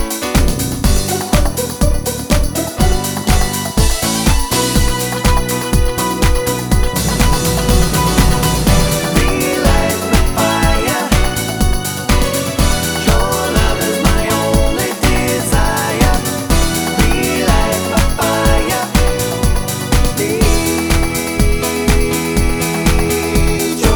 No Female Part Pop (1990s) 3:59 Buy £1.50